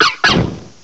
cry_not_fennekin.aif